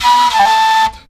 FLUTELIN03.wav